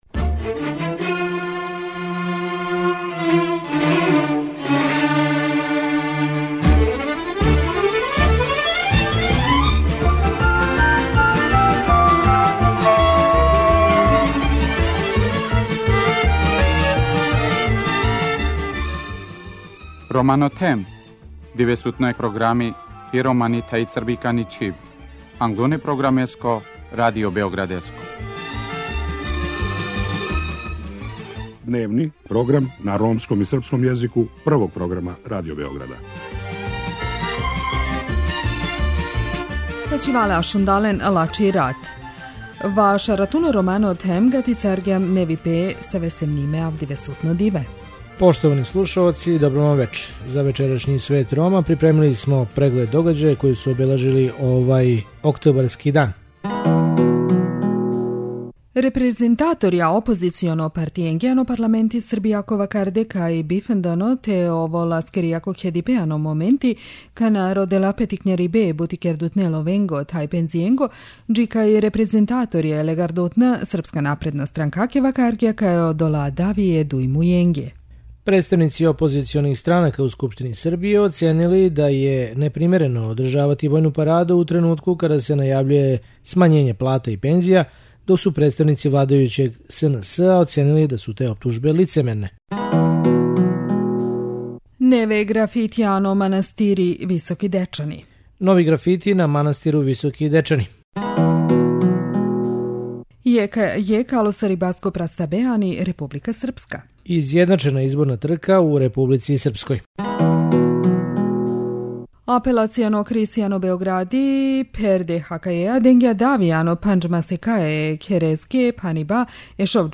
Чућемо причу о три припаднице ове мањине које су започеле сопствени бизнис, а потом следи репортажа о Малали Јусуфзеи овогодишњој добитници Нобелове награде за мир.